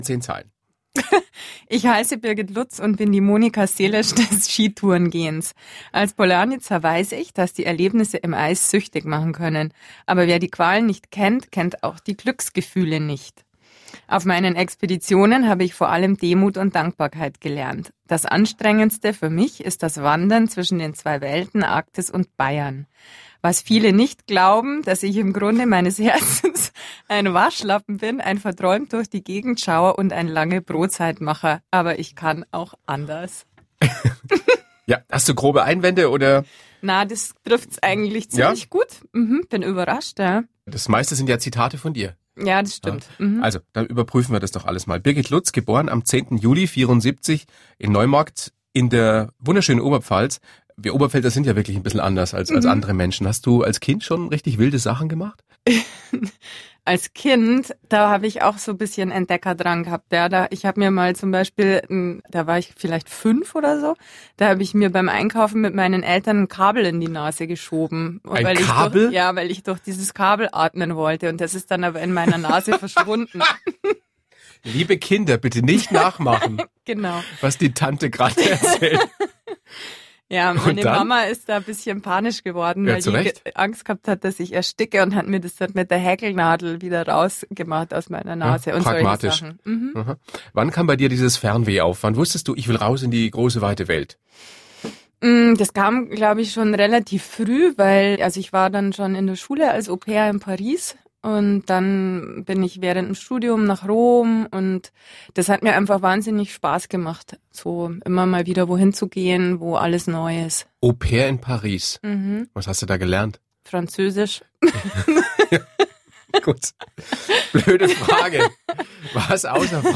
Interviews in the Radio